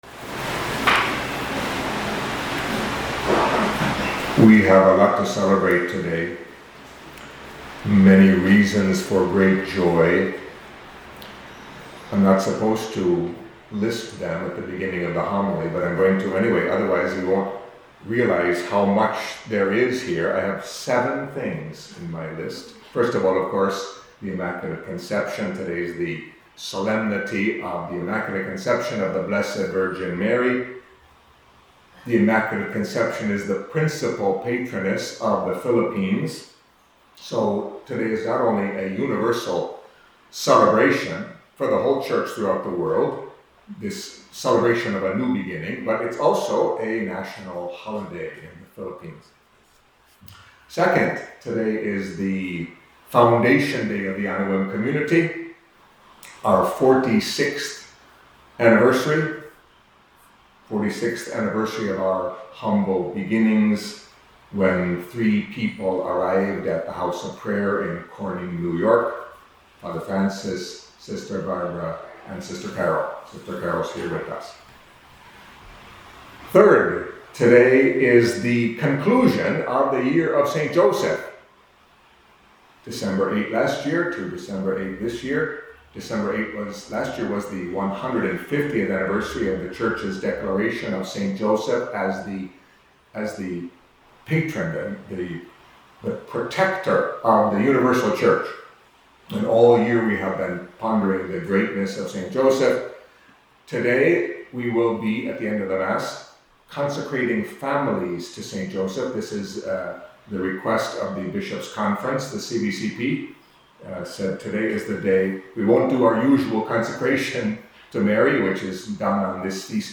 Catholic Mass homily for the Solemnity of The Immaculate Conception of the Blessed Virgin Mary